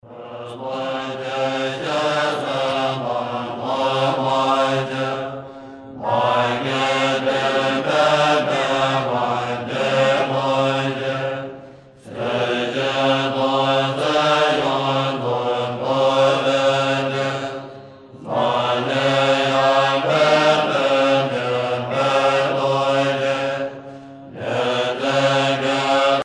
enregistrement réalisé dans le grand temple du monastère de Dharamsala
Pièce musicale éditée